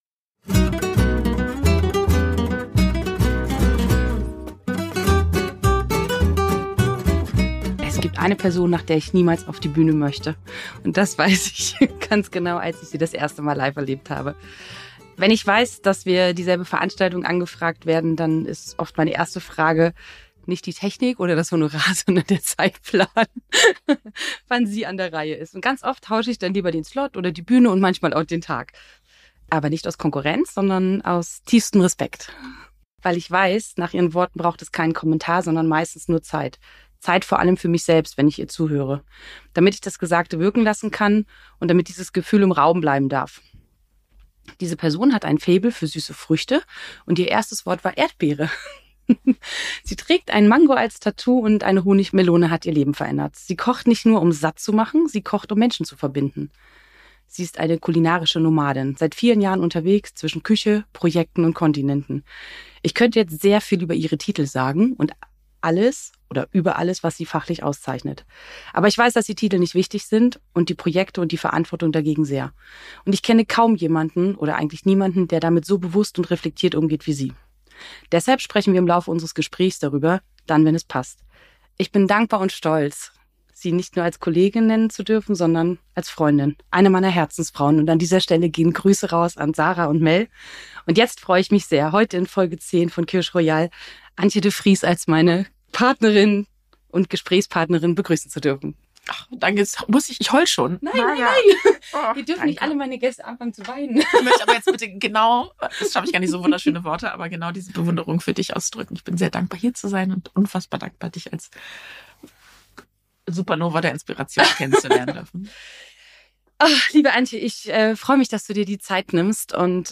Ein Gespräch über Essen als Verbindung.